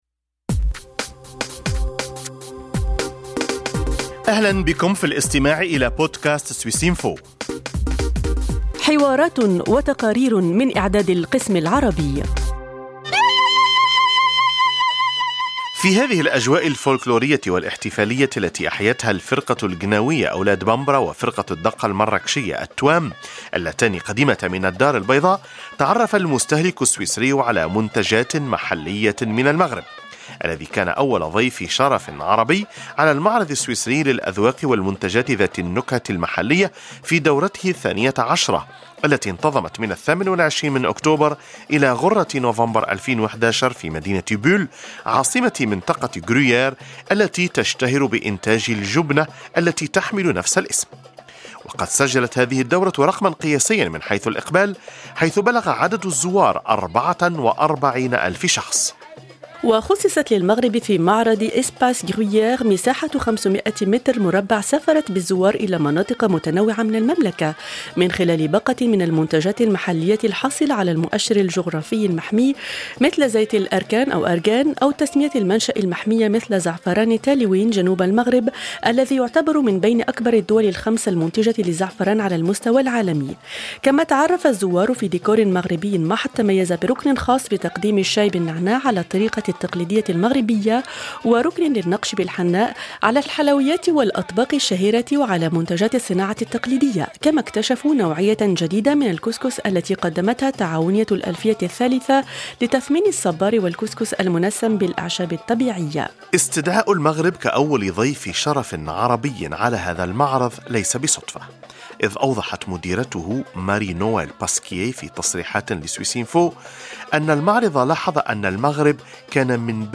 جولة في جناح المغرب الذي عرّف باستراتيجية حمايته للمنتجات المحلية مثل "زيت الأركان" (أرغان) و"زعفران تالوين" بمناسبة مشاركته كأول ضيف شرف عربي في المعرض السويسري للأذواق والمنتجات ذات النكهة المحلية في دورته الثانية عشرة التي انتظمت من 28 أكتوبر إلى 1 نوفمبر 2011 في Bulle بكانتون فريبورغ.